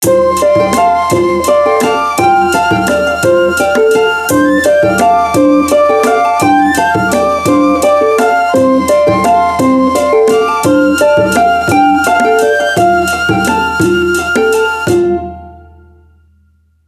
китайские , флейта